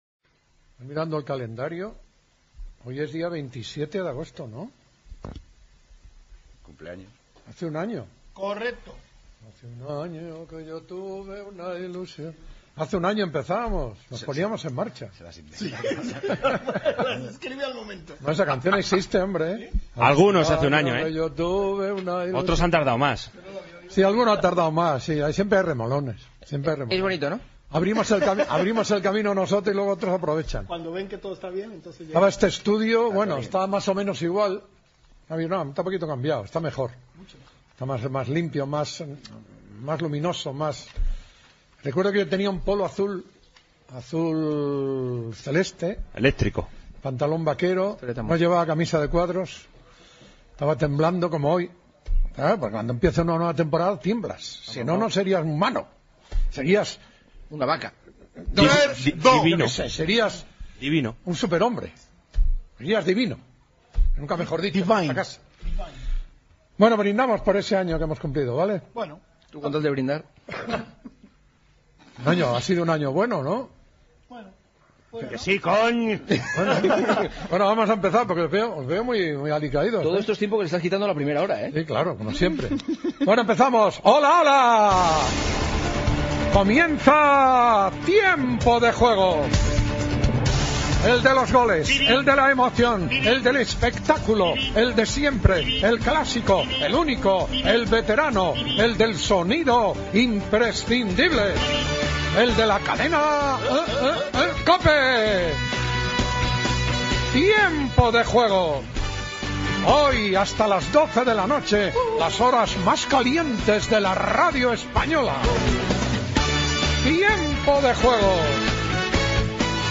Informació de l'etapa de la Vuelta Ciclista a España. Horaris dels partits de primera i segona diviisó amb informació des d'un bar proper al Mini Estadi del Futbol Club Barcelona (en no poder entrar-hi les ràdios). Invitació a la participació, cançó del programa.
Gènere radiofònic Esportiu